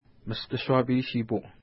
ID: 227 Longitude: -61.2027 Latitude: 55.8646 Pronunciation: mistə-ʃwa:pi:-ʃi:pu Translation: Mister Swaffield River Feature: river Explanation: Named in reference to lake Mishti-Shuapi (no 217) from which it flows.